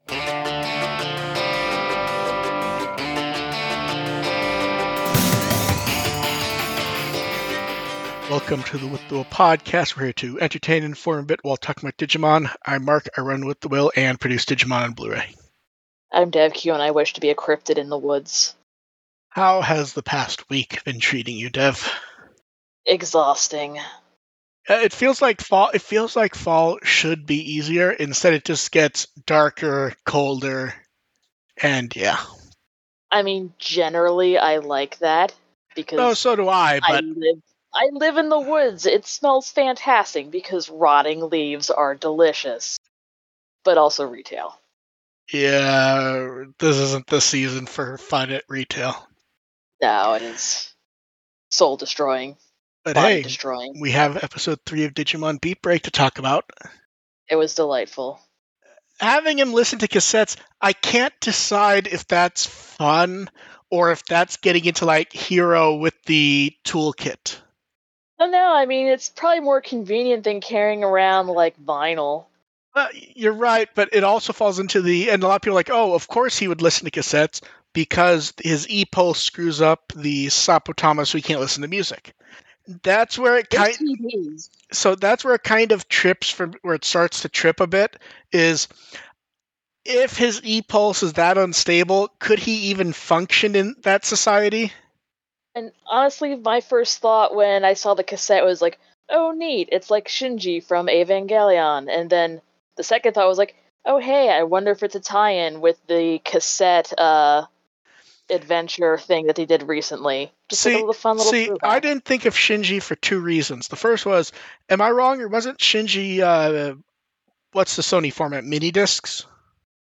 The podcast audio is the livestream clipped out, with an intro and outtro added, along with some tweaking to try and improve audio quality.